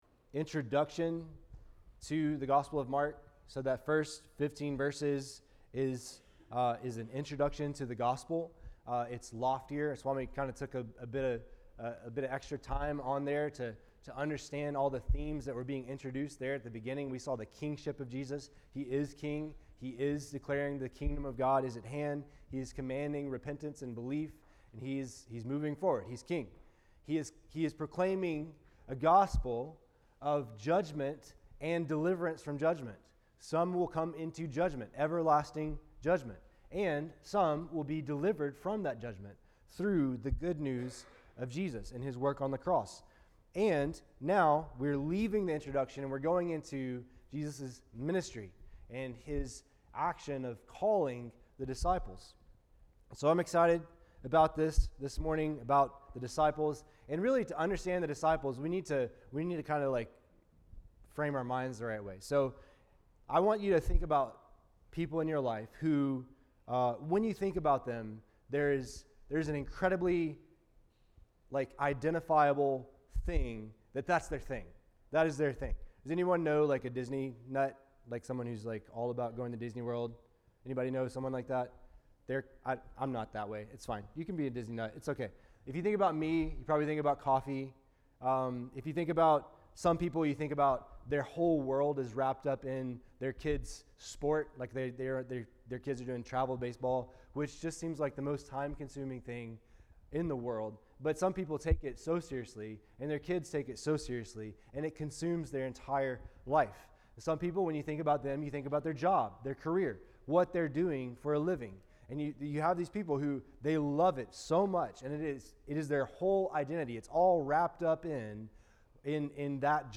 Kahului Baptist Church Sermons | Kahului Baptist Church